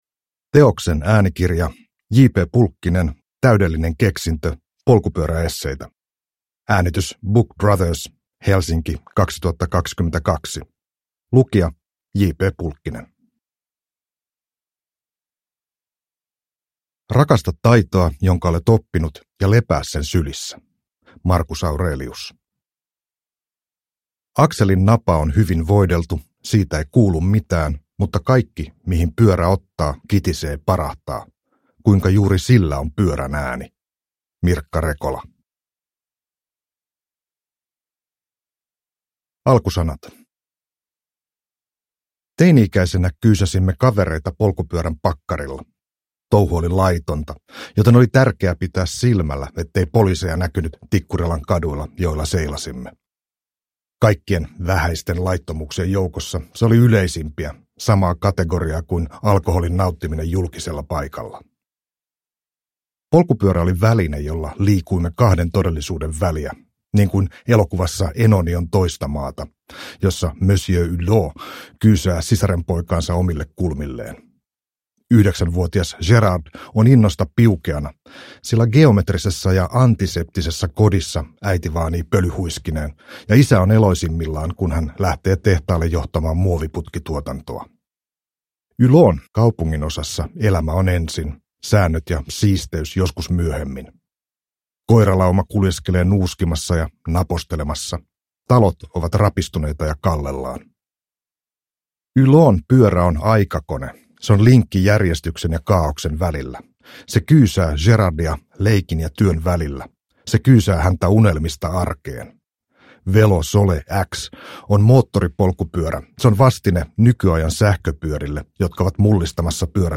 Täydellinen keksintö – Ljudbok – Laddas ner